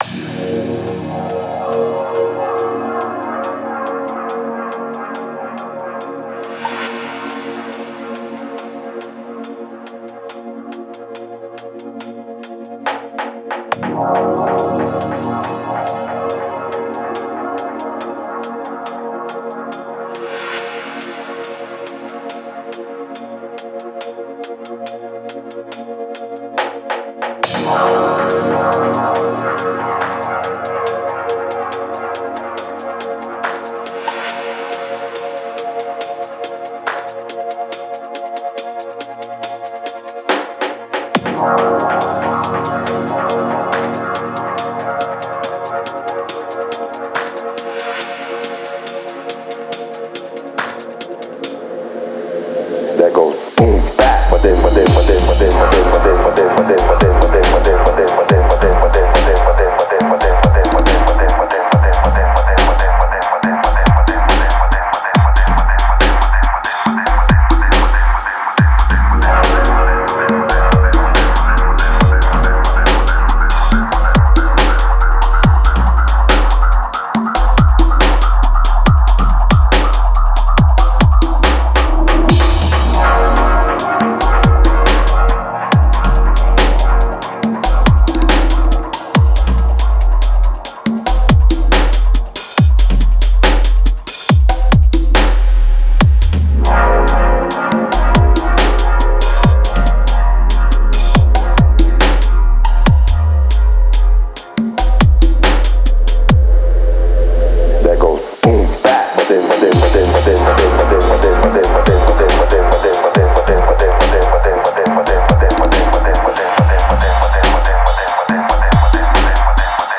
Genre: Deep Dubstep, Dub, Electronic.